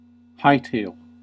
Uttal
Synonymer skedaddle flee hightail it Uttal US Okänd accent: IPA : /ˈhaɪ.teɪl/ Ordet hittades på dessa språk: engelska Ingen översättning hittades i den valda målspråket.